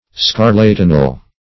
-- Scar`la*ti"nal , a. -- Scar*lat"i*nous (# or #), a. [1913 Webster]